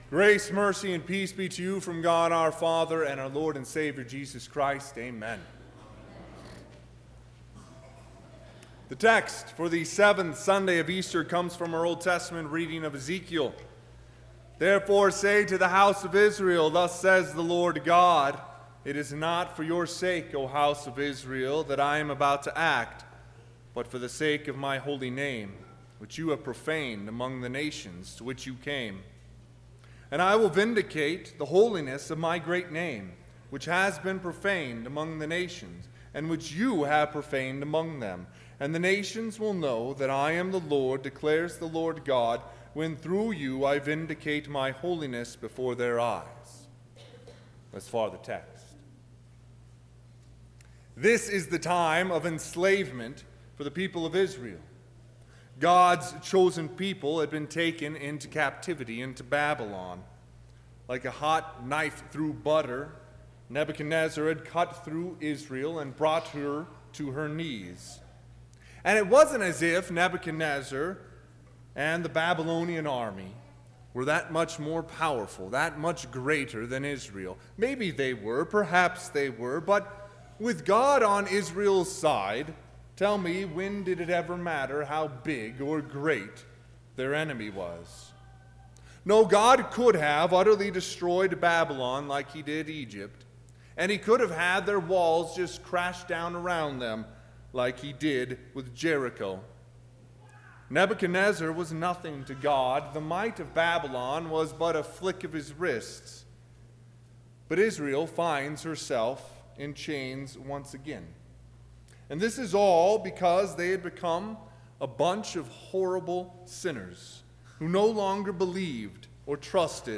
Seventh Sunday of Easter - Exaudi
Sermon – 6/2/2019
Sermon_June2_2019.mp3